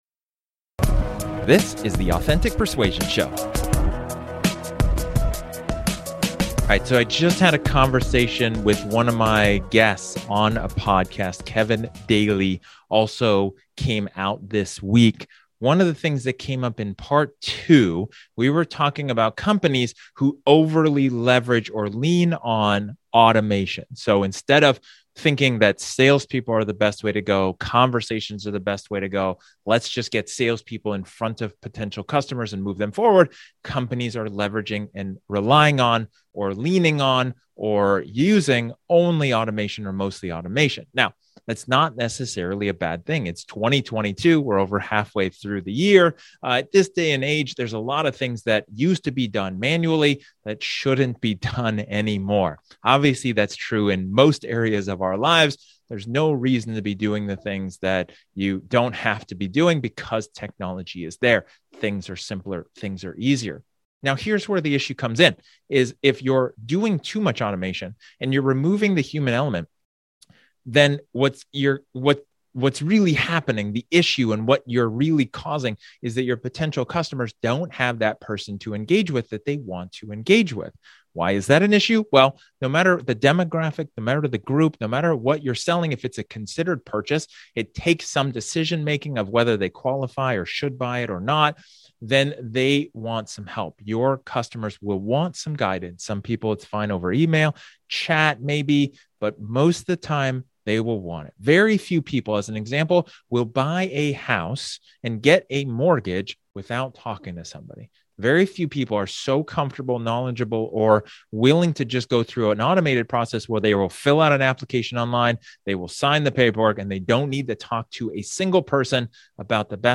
In this solo episode, I talk about leveraging and leaning on automation in your sales process.